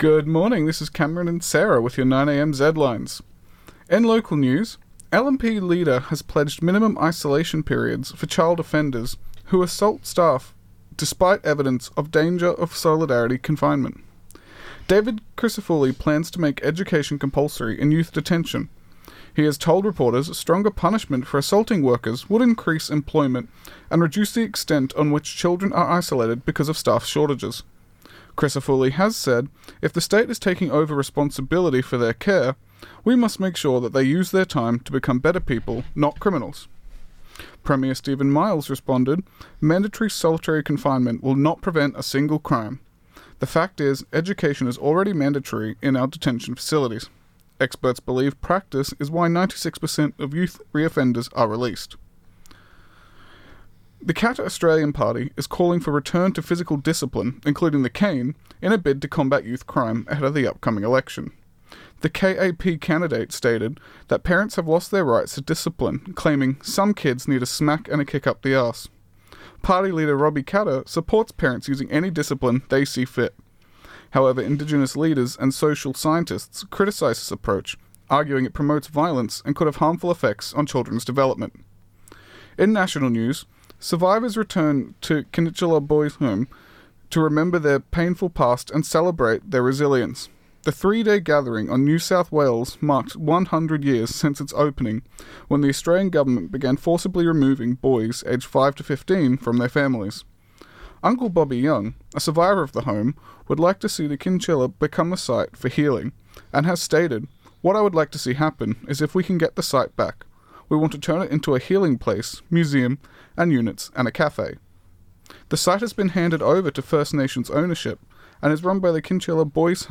Zedlines Bulletin 9AM MON 2110.mp3 (3.59 MB)